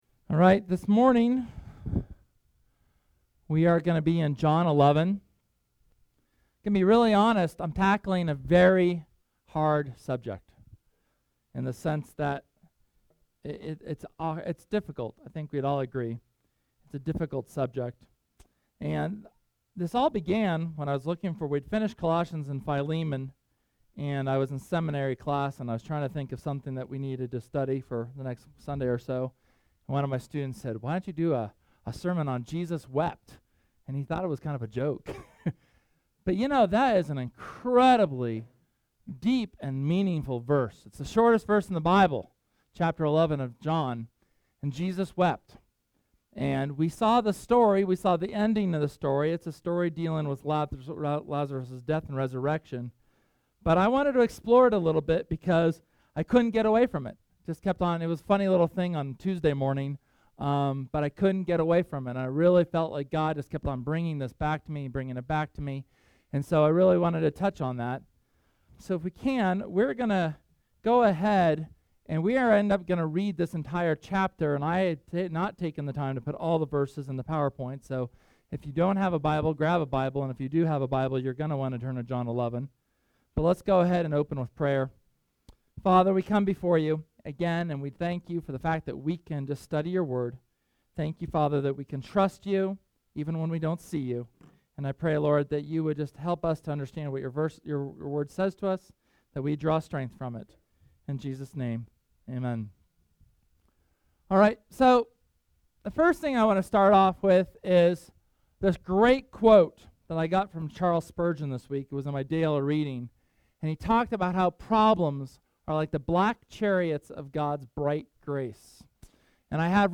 SERMON: Jesus Wept – Church of the Resurrection